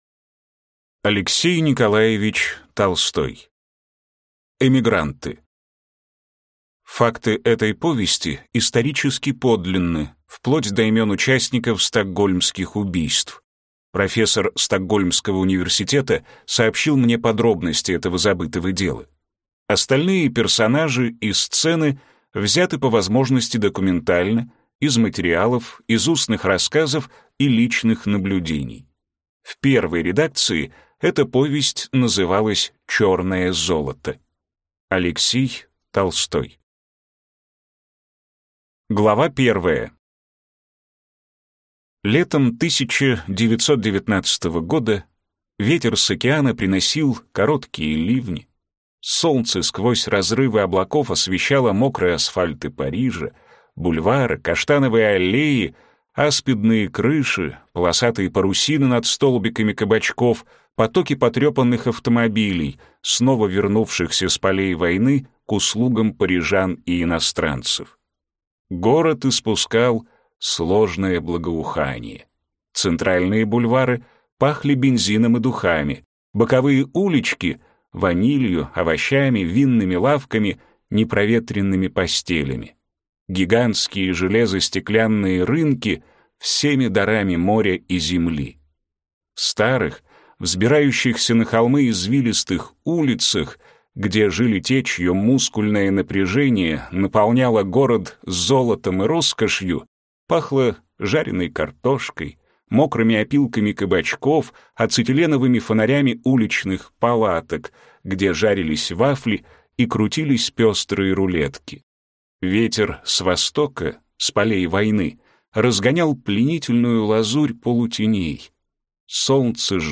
Аудиокнига Эмигранты | Библиотека аудиокниг